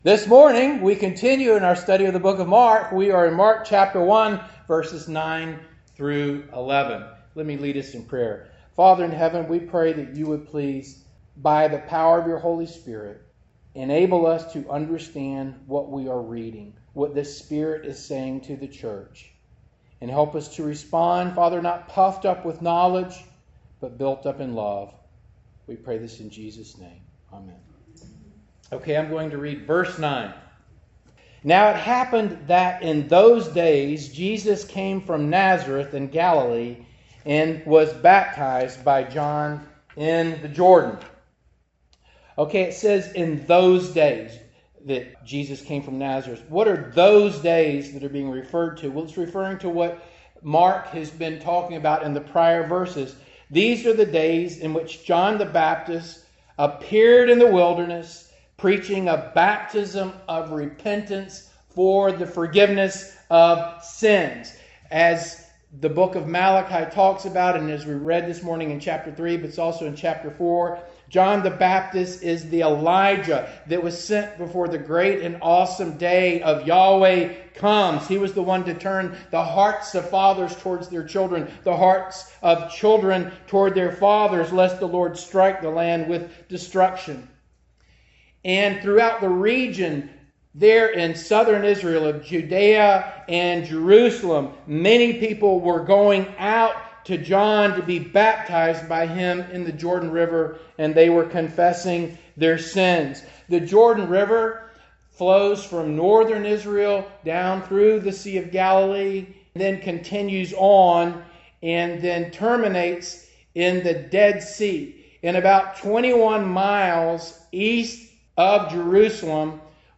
Passage: Mark 1:9-11 Service Type: Morning Service